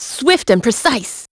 Ripine-Vox_Skill4-02.wav